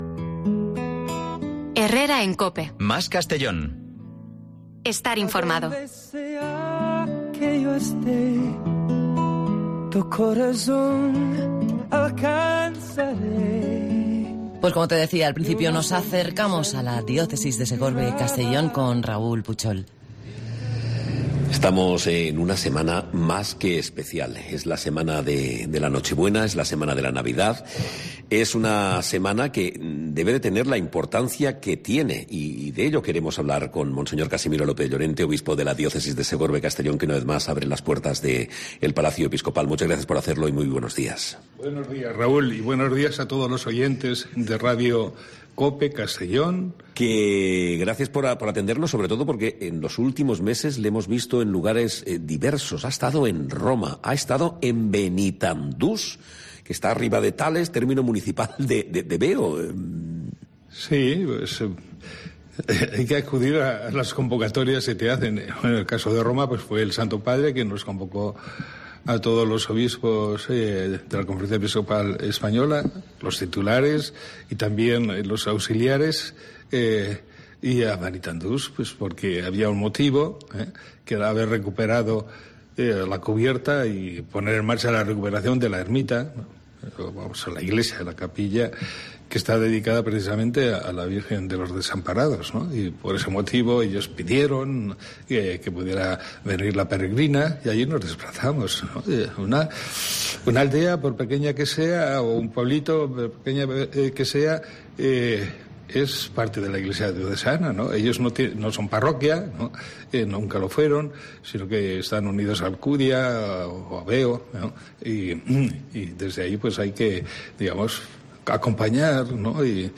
Desde la sede episcopal de la Diócesis de Segorbe-Castelló en COPE hemos sido recibidos por el obispo Casimiro López Llorente .